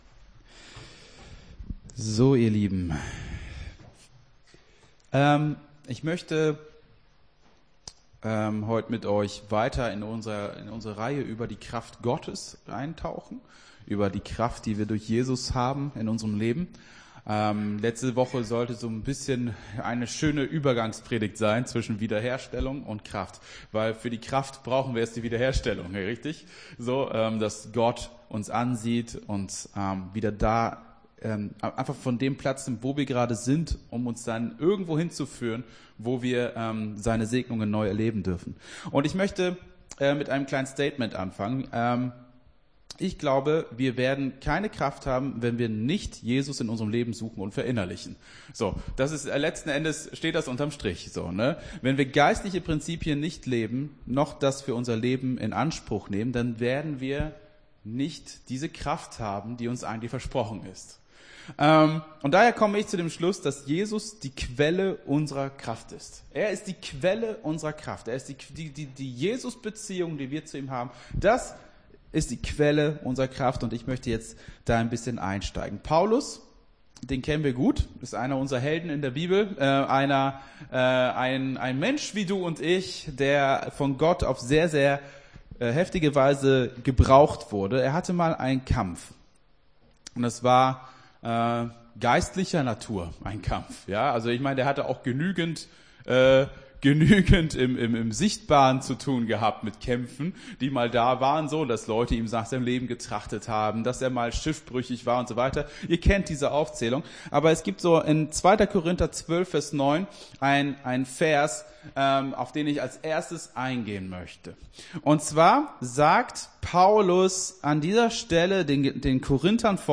Gottesdienst 06.03.22 - FCG Hagen